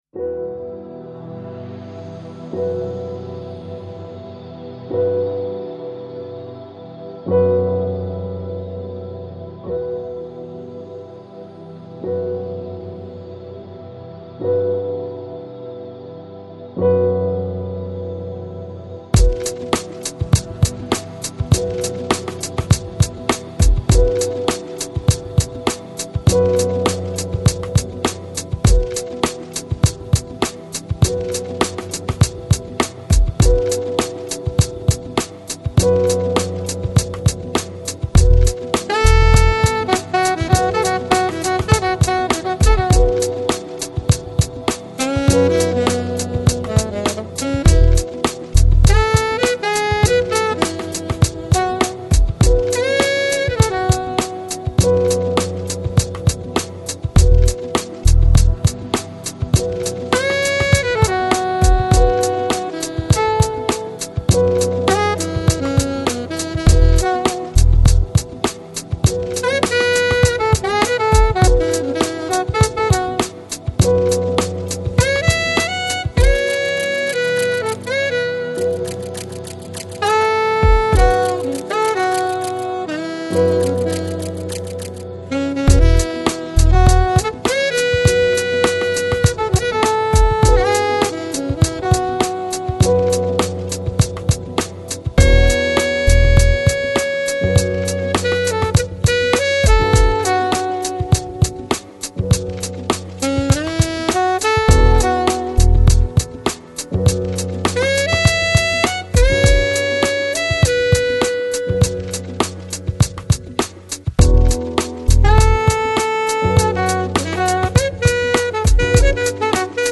Electronic, Downtempo, Chill House, Chillout